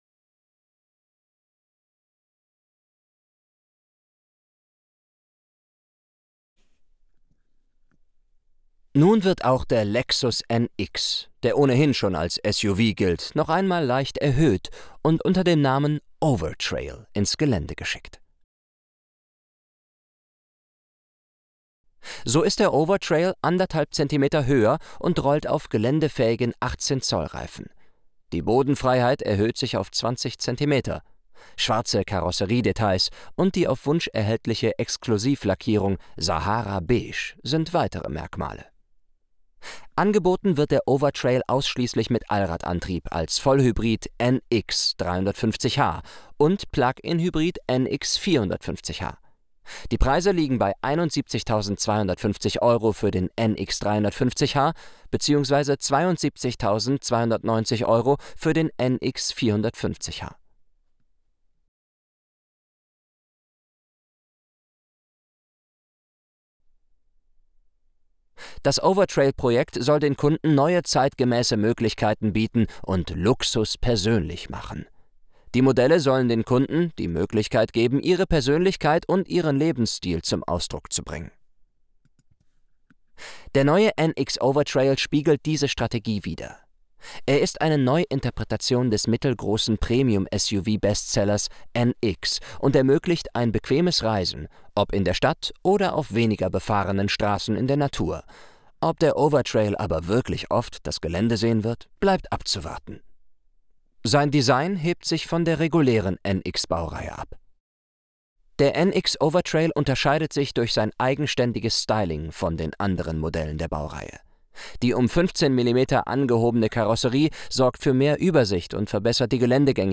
lexus_nx_24_de_vo.wav